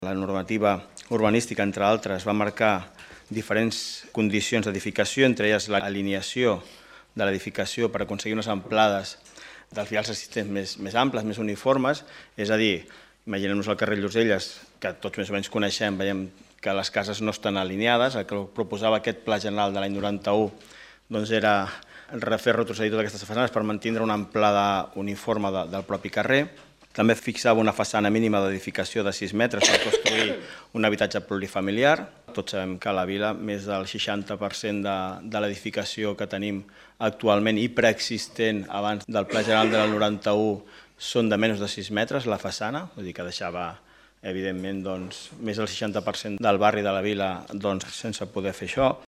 L’Ajuntament de Martorell va aprovar, en el Ple Ordinari d’ahir a la nit, una modificació puntual del Pla General d’Ordenació Urbana (PGOU) de Martorell de gran rellevància per La Vila.
Albert Fernández, regidor de Planificació Urbanística